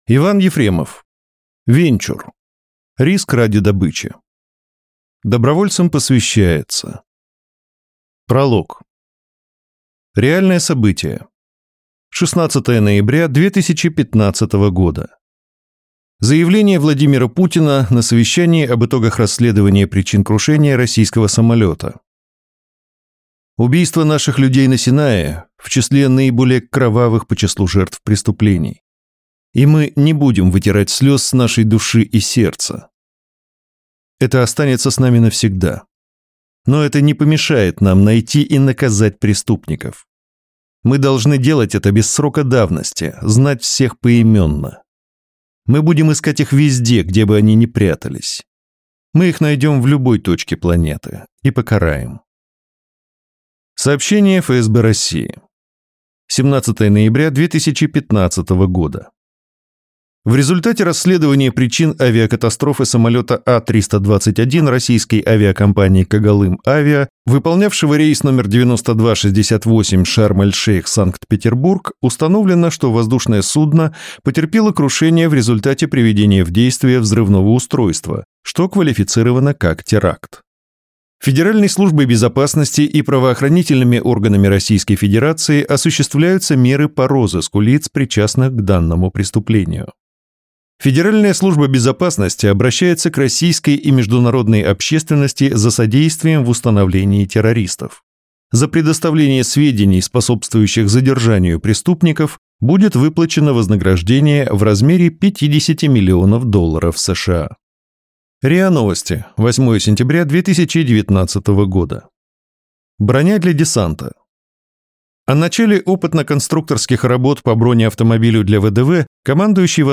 Аудиокнига Венчур. Риск ради добычи | Библиотека аудиокниг
Прослушать и бесплатно скачать фрагмент аудиокниги